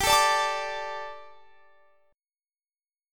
D5/G chord